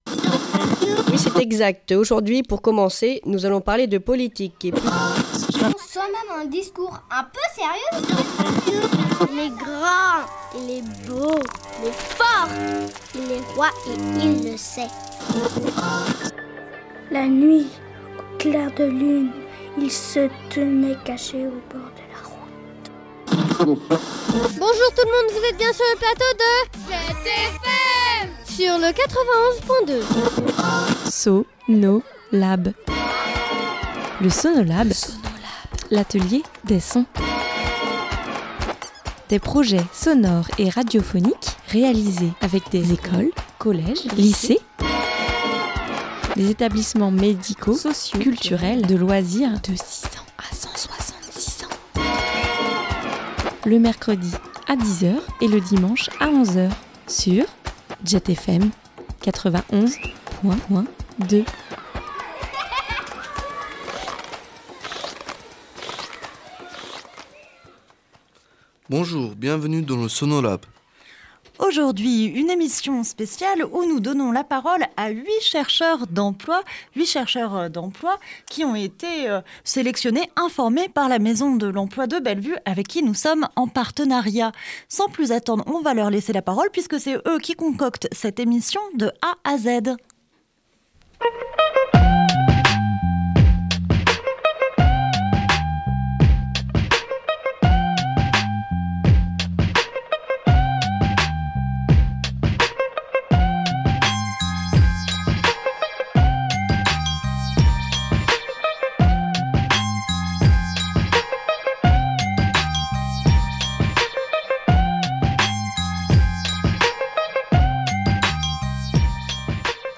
Le Sonolab vous fait découvrir l’émission réalisée par des chercheurs d’emploi et salariés en insertion du Grand Bellevue. - Sonolab
Ils sont 8 chercheurs d’emploi et salariés en parcours d’insertion, et ils sont venus à Jet FM pendant 4 jours pour un atelier au cours duquel ils ont pu réaliser des reportages terrain ainsi qu’une émission autour de la découverte de différents métiers et d’univers professionnels.